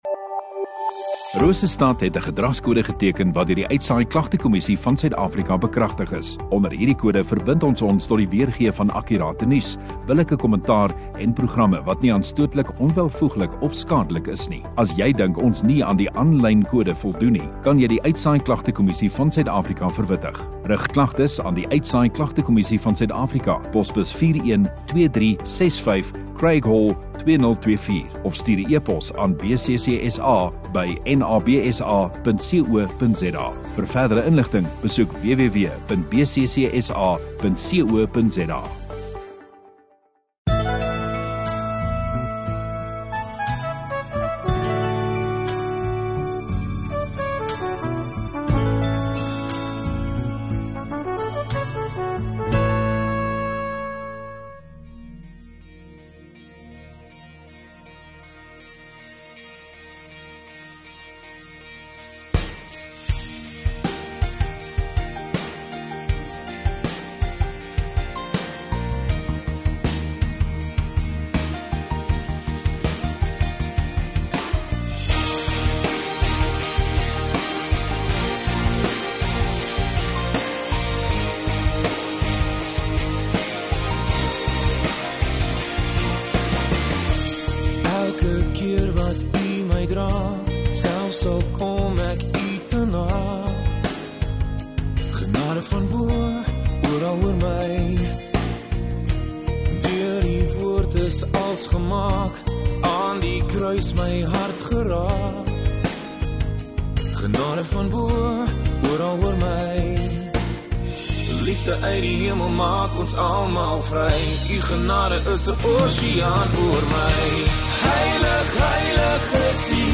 29 Nov Saterdag Oggenddiens